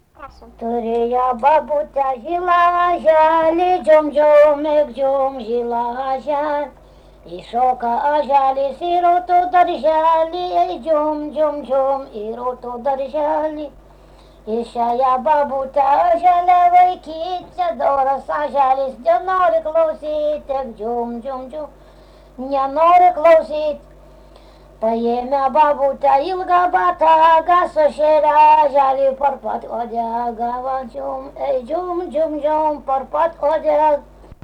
Erdvinė aprėptis Rageliai
Atlikimo pubūdis vokalinis